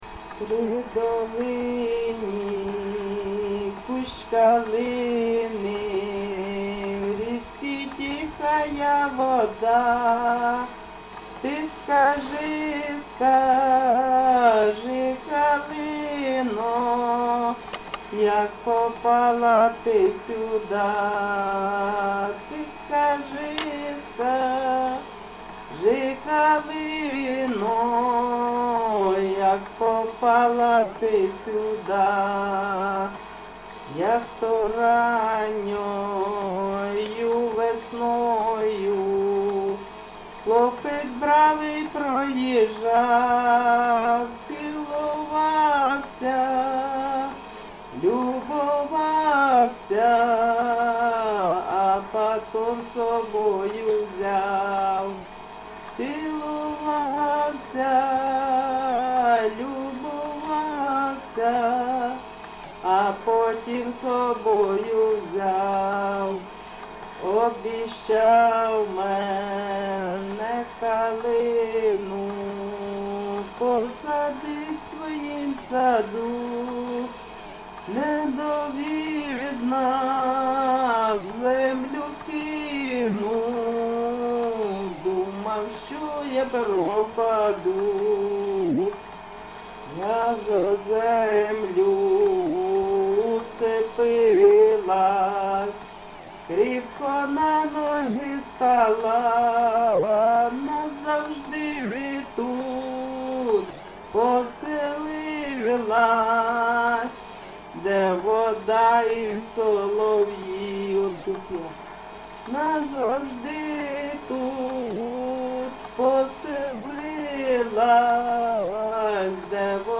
ЖанрПісні з особистого та родинного життя, Сучасні пісні та новотвори
Місце записус. Олександро-Калинове, Костянтинівський (Краматорський) район, Донецька обл., Україна, Слобожанщина